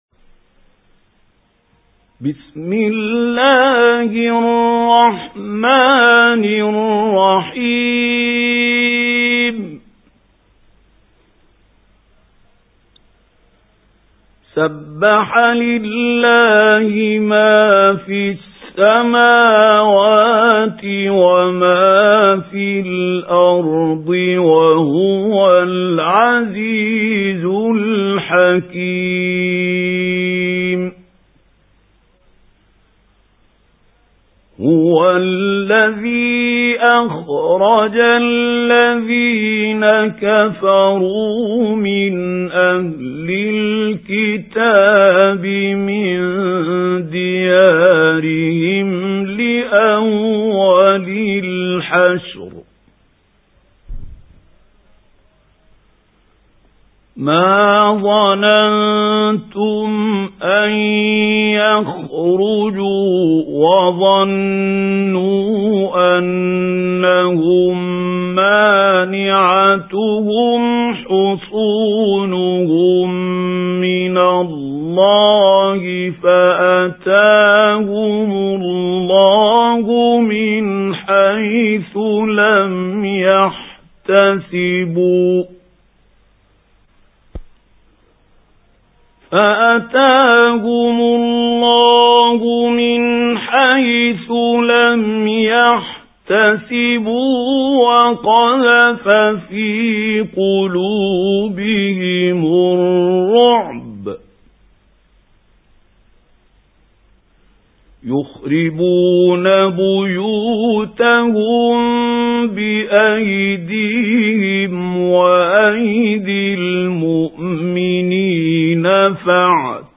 سُورَةُ الحَشۡرِ بصوت الشيخ محمود خليل الحصري